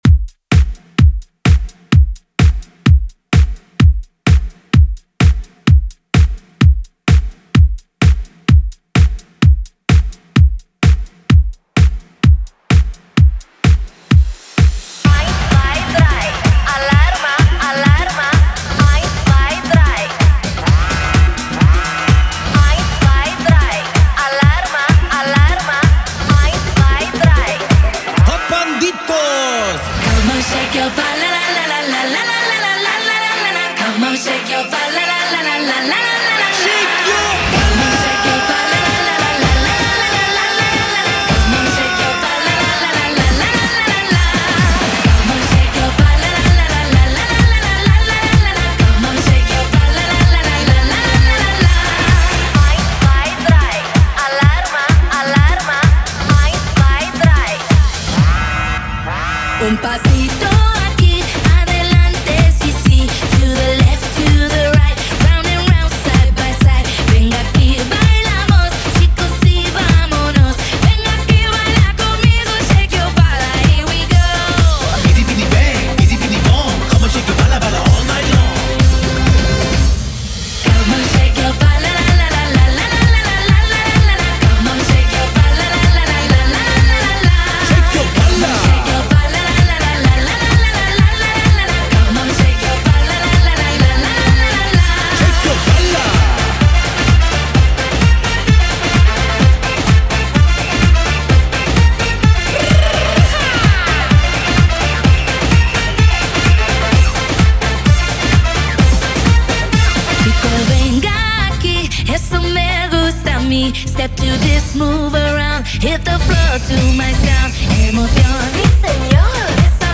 Genre: 90's Version: Clean BPM: 102